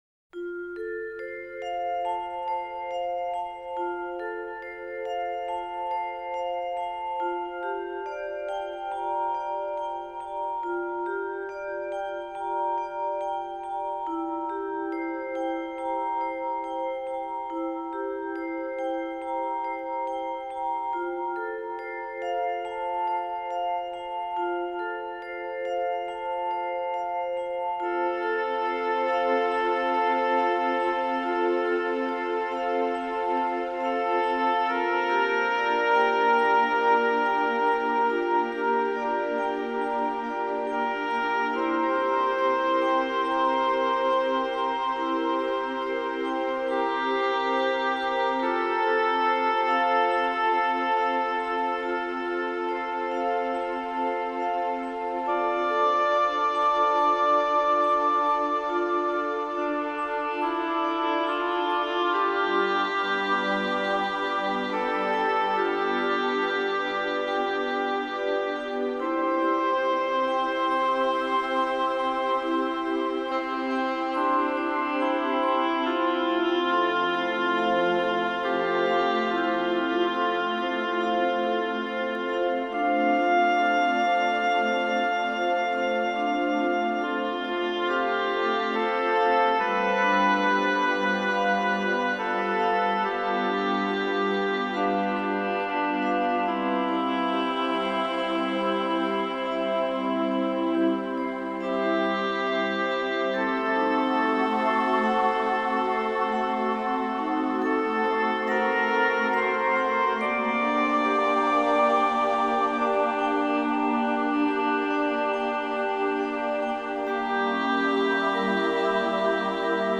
Genre: New Age, Meditative.